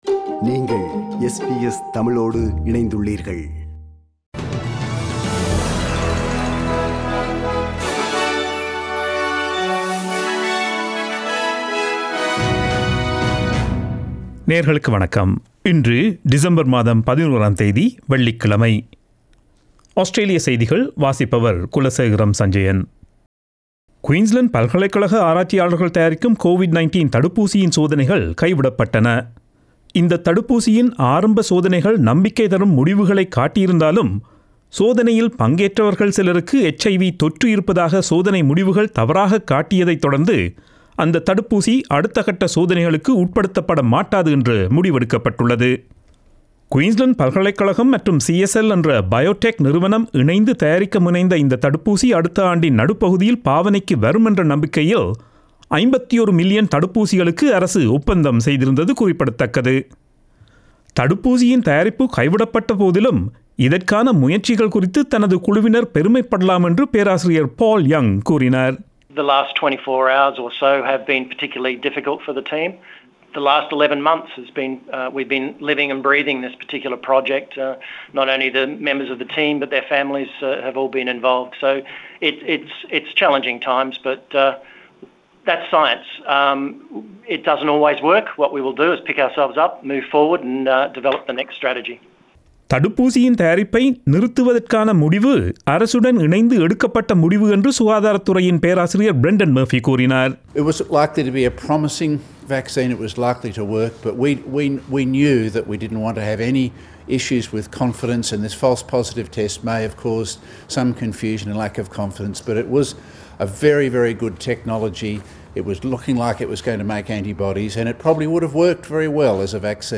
Australian news bulletin for Friday 11 December 2020.